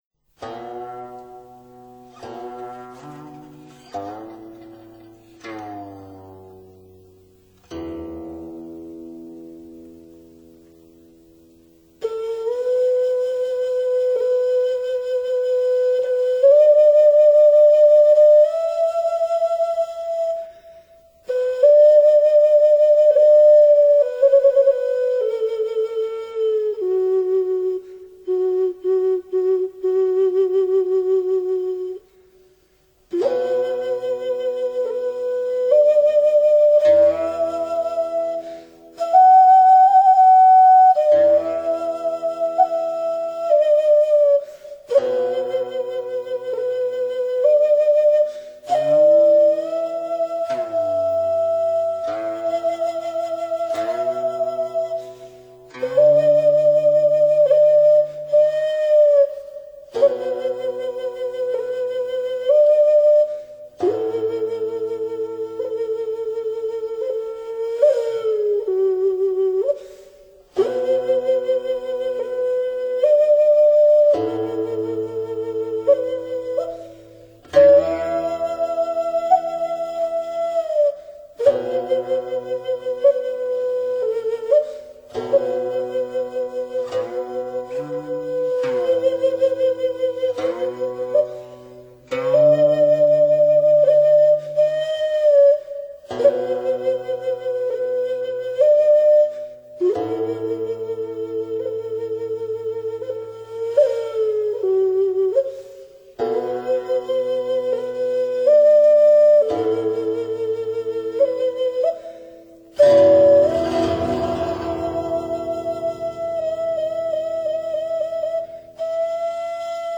[12/8/2010]请静静地去聆听，缓缓地去感受，哀怨凄婉的美：古埙曲《衰郢》